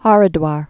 (härĭ-dwär, hûr-) or Har·dwar (härdwär, hûr-)